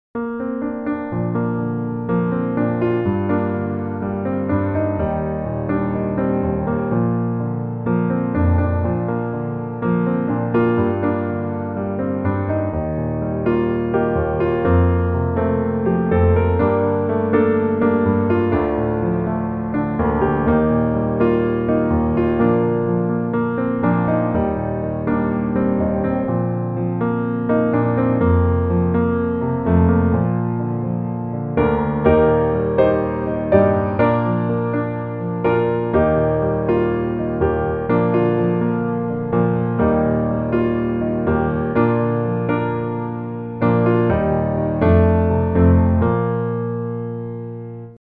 Bb Majeur